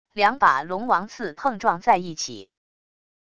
两把龙王刺碰撞在一起wav音频